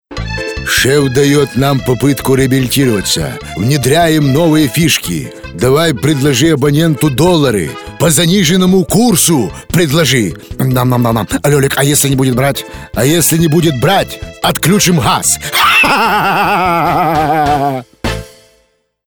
Parodiya_na_Papanova.mp3